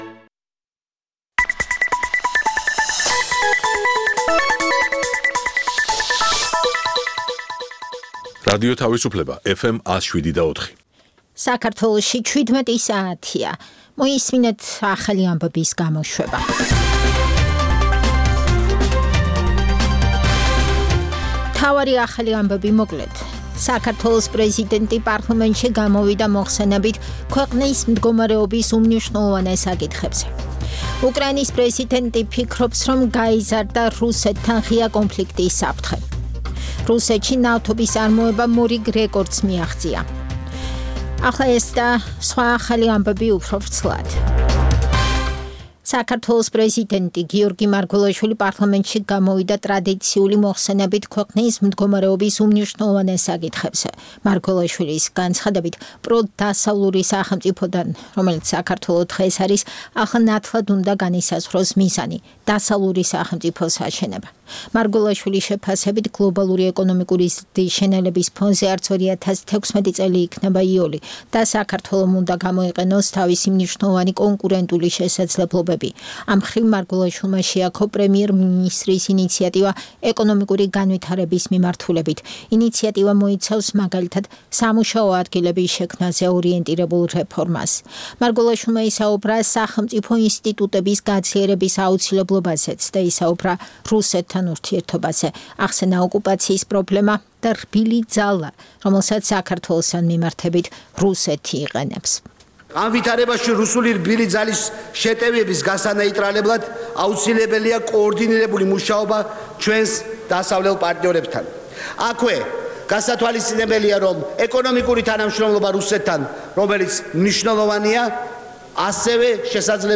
ცნობილ ექსპერტებთან ერთად, გადაცემაში მონაწილეობენ საზოგადოებისთვის ნაკლებად ცნობილი სახეები, ახალგაზრდა სამოქალაქო აქტივისტები. გამოყენებულია "რადიო თავისუფლების" საარქივო მასალები, რომელთაც გადაცემის სტუმრები "დღევანდელი გადასახედიდან" აფასებენ. გადაცემა ეთერში გადის ყოველ სამუშაო დღეს, 17:00-ზე, სიხშირეზე FM 107,4.